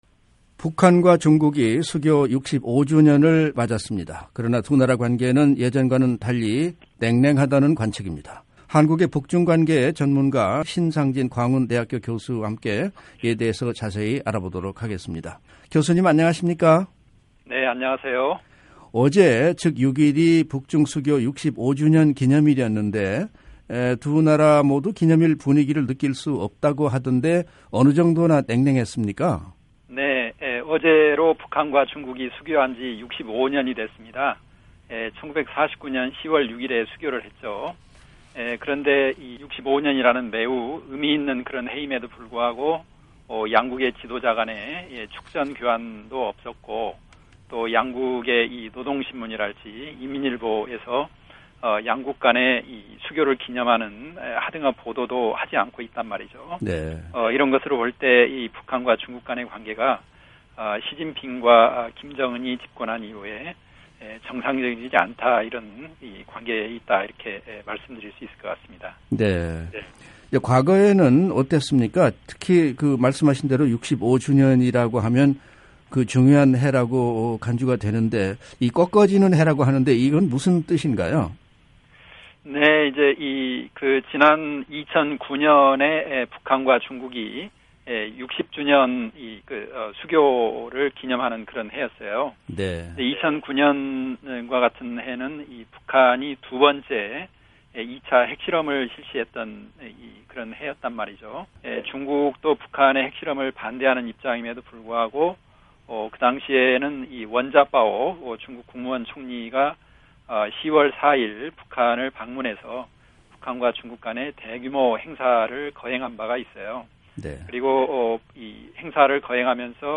[인터뷰: